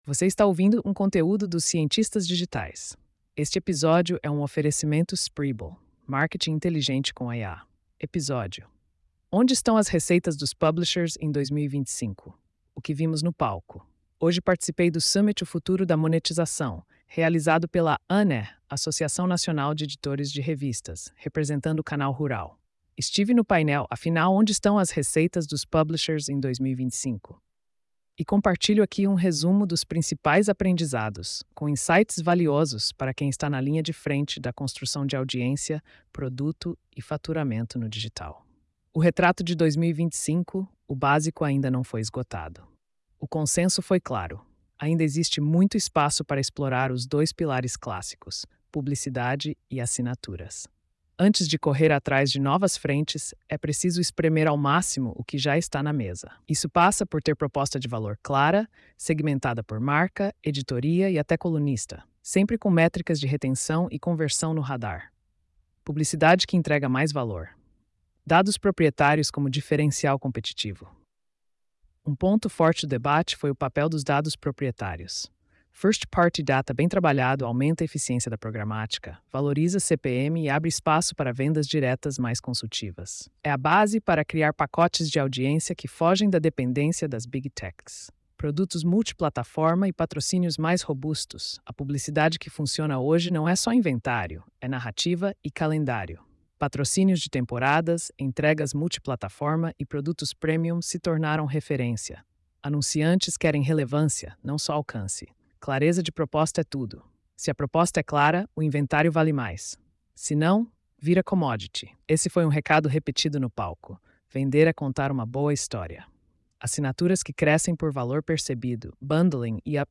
post-4445-tts.mp3